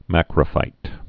(măkrə-fīt)